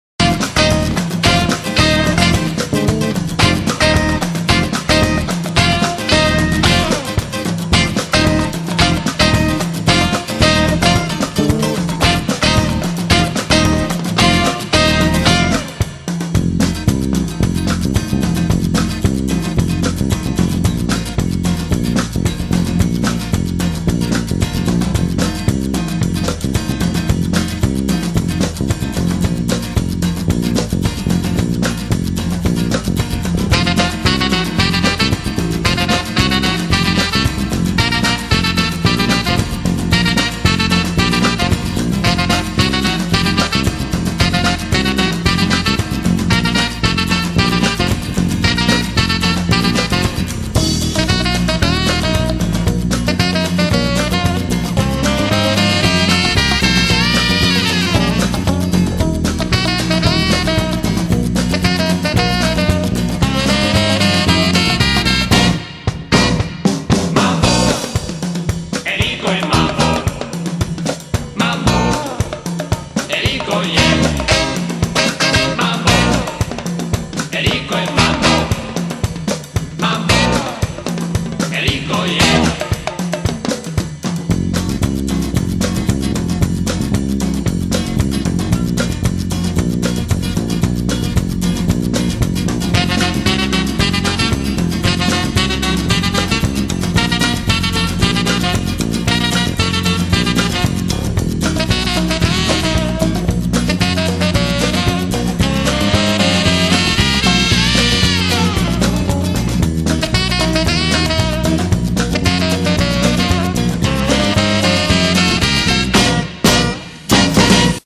录DANCE），是一张具有曼波神韵的音乐专辑。
门金曲，旋律性强而富有动感，散发着无穷的时尚气息。